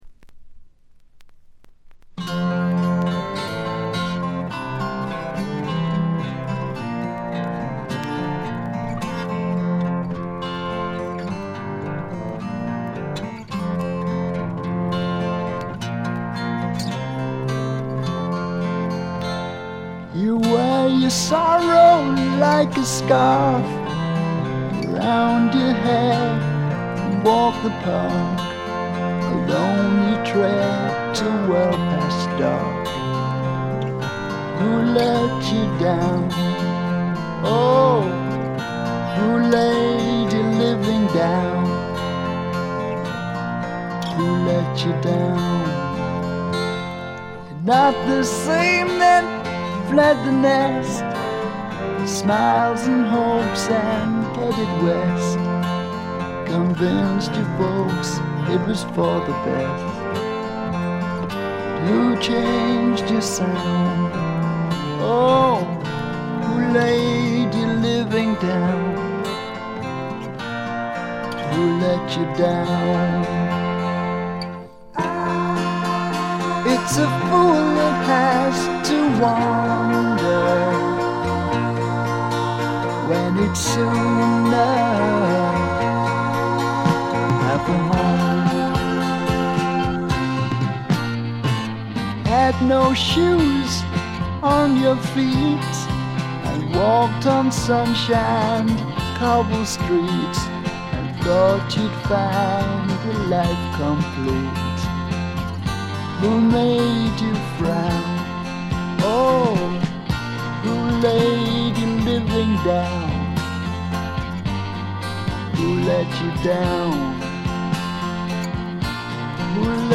ところどころで軽いチリプチ。
絞り出すような味のある渋いヴォーカルが何と言っても彼の最大の持ち味。
試聴曲は現品からの取り込み音源です。